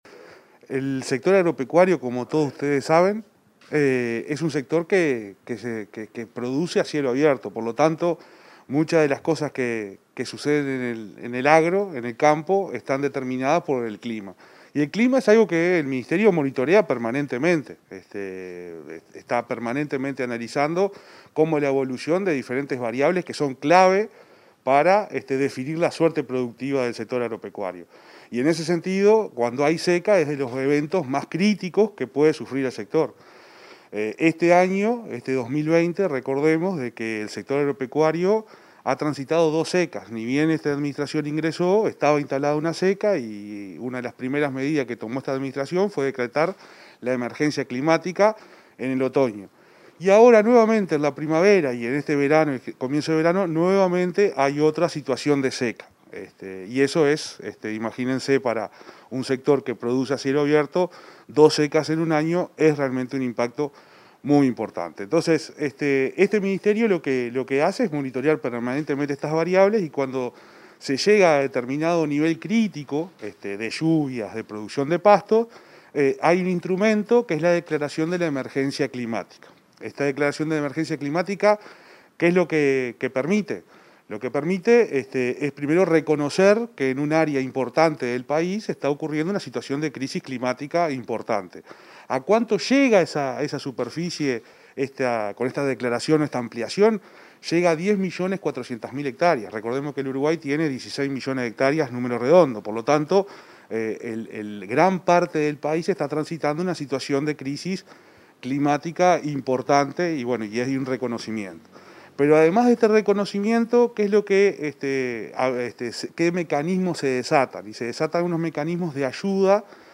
Declaraciones de Juan Ignacio Buffa sobre extensión de emergencia agropecuaria